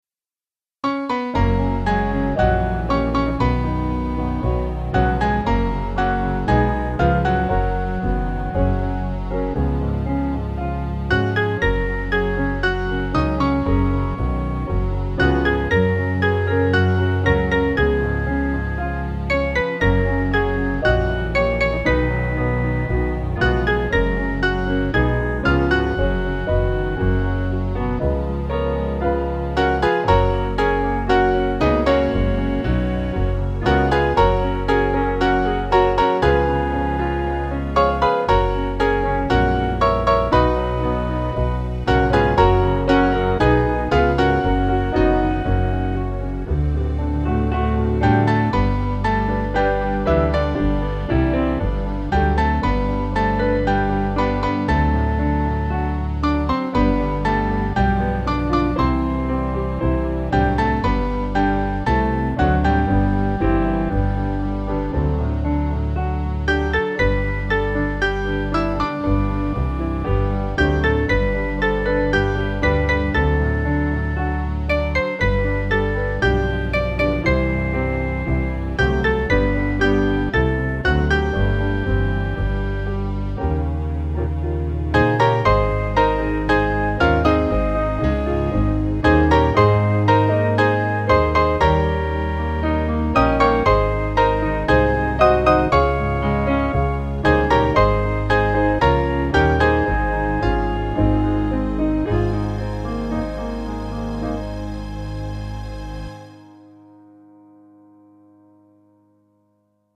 Mainly Piano
(CM)   5/Gb-G-Ab 392.2kb